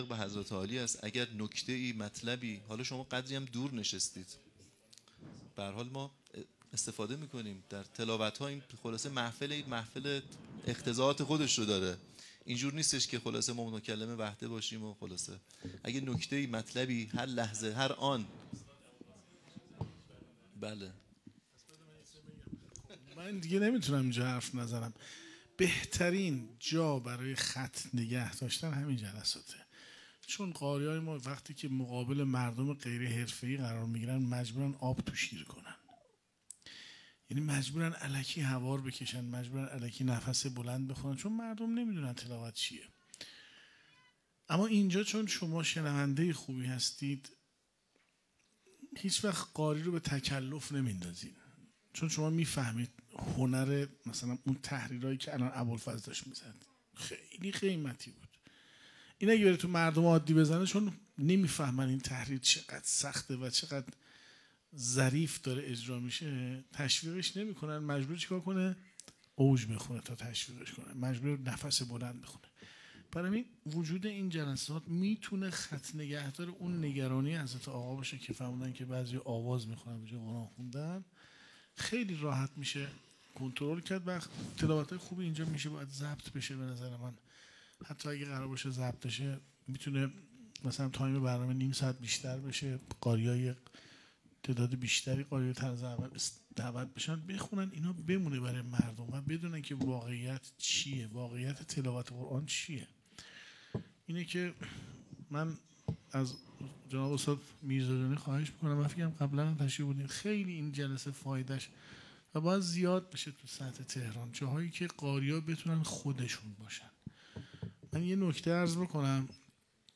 احمد ابوالقاسمی در بیست و دومین محفل مجمع قرآنیان اسلامشهر (منادی) که شب گذشته، ۲۹ اردیبهشت‌ماه برگزار شد، گفت: بهترین مکان برای خط‌نگه داشتن و عمل به منویات مقام معظم رهبری همین جلسات قرآن است.